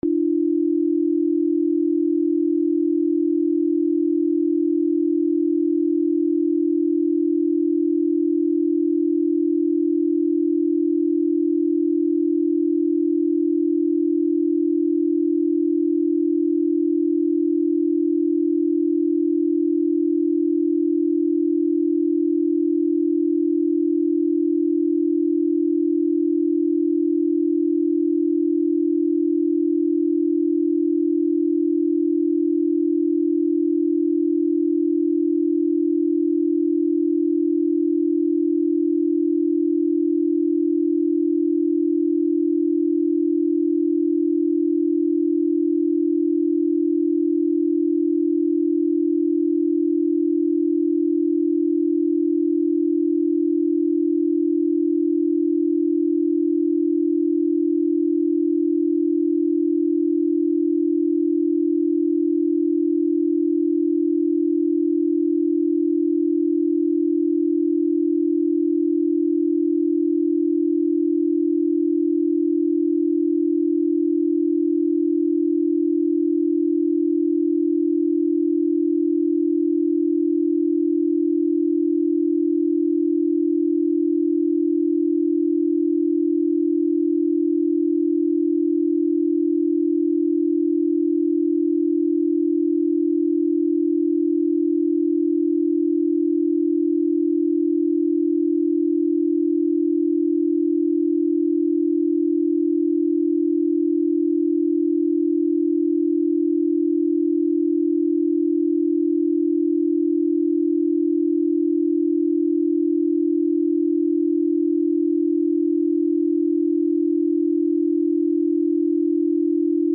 Download 40Hz Binaural Beats: Enhance Your Brain Power
Binaural beats are a kind of soundwave therapy that takes use of the brain’s capacity to detect a second tone when two distinct frequencies are equally played in each ear. It has been shown that 40Hz binaural beats especially increase brain function and mental clarity.